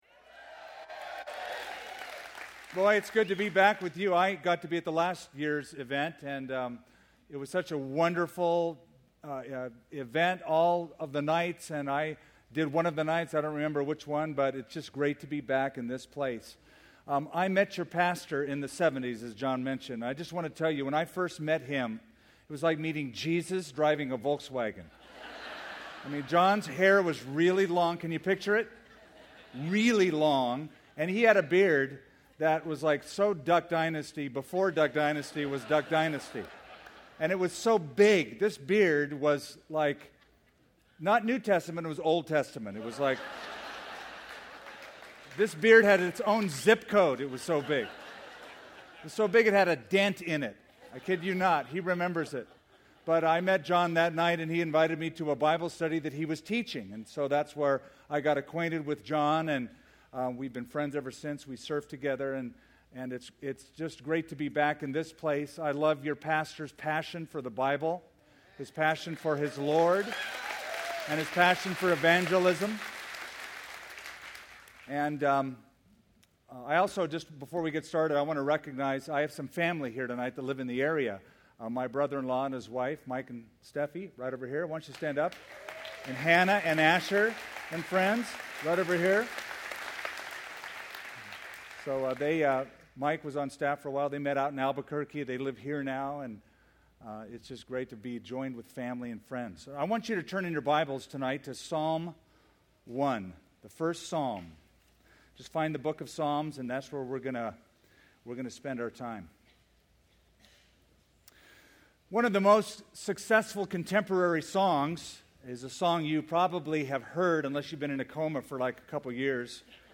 Sermons Conferences 5 Nights Of Revival (2014) Happy Happy Psalms 1 • July 6